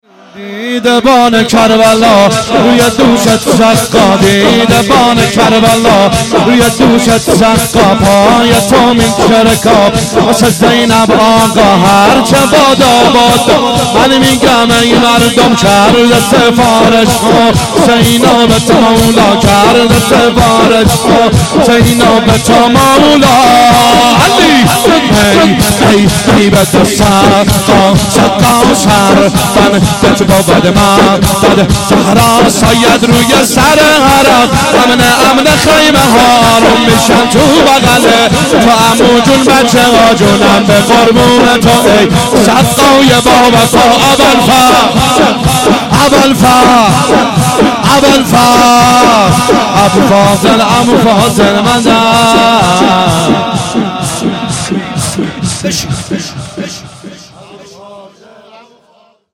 شور
جلسه هفتگی ( 23 بهمن ماه )